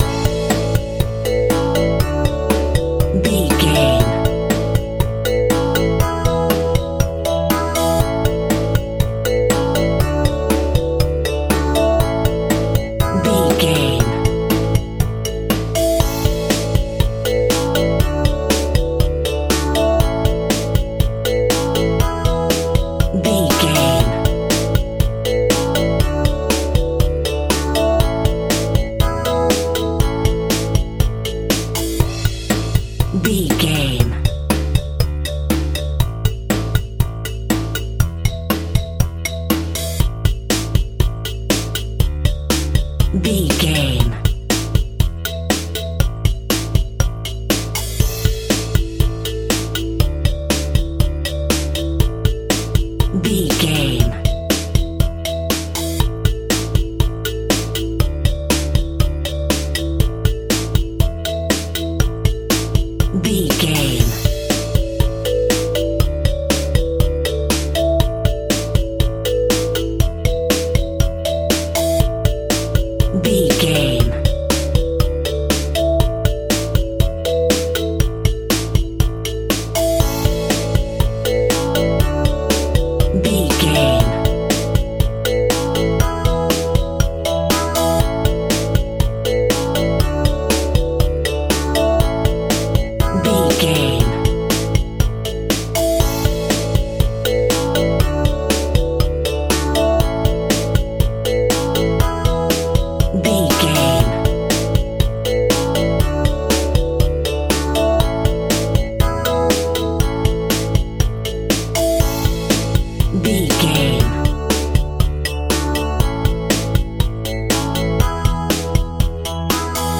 Saturday Morning Kids Music.
Uplifting
Ionian/Major
Acoustic Piano
drums
bass guitar
synths
marima
vibraphone
xylophone